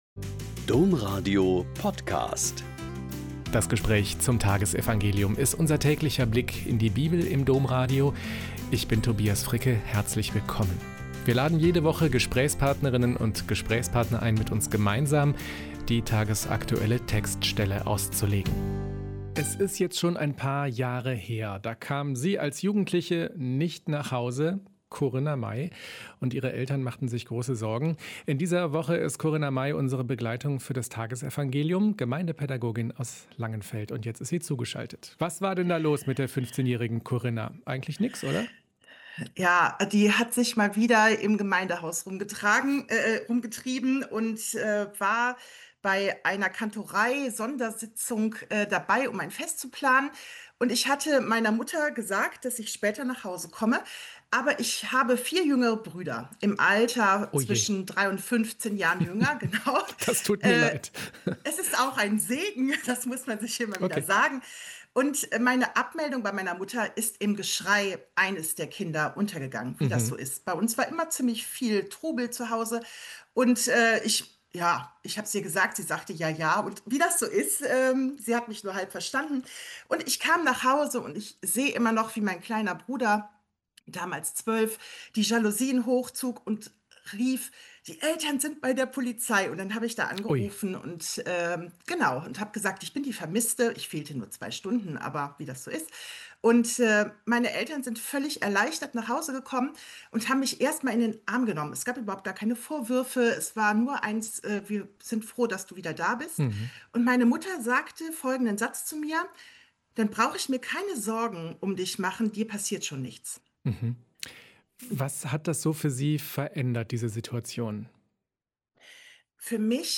Mt 24,42-51 - Gespräch